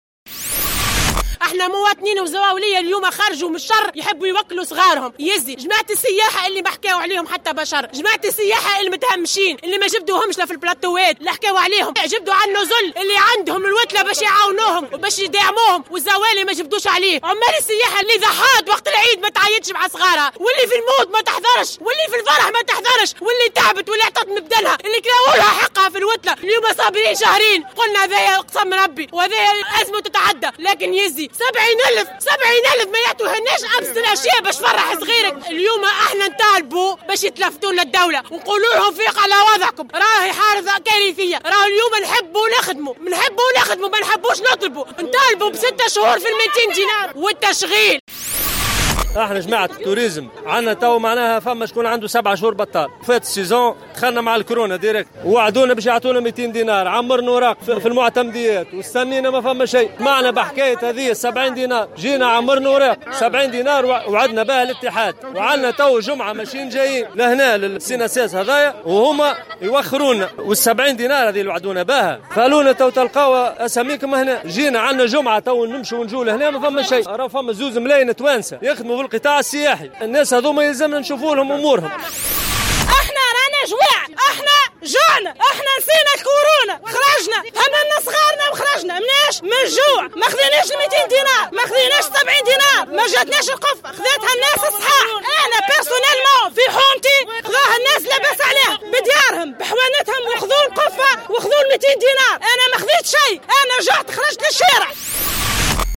نفذ اليوم الجمعة 22 ماي 2020، أعوان السياحة بسوسة وقفة إحتجاجية أمام مقر الصندوق الوطني للضمان الإجتماعي بباب بحر سوسة ، للمطالبة بمنحة عيد الفطر بقيمة 70 د .
وندّدوا في تصريح للجوهرة أف أم بسياسة المماطلة التي تنتهجها السلط المعنية في إسناد هذه المنحة التي يتمتعون بها كل سنة ،خاصة وأنهم لم ينتفعوا بالمساعدات الظرفية المسندة في إطار الأزمة الوبائية وفق تصريحهم.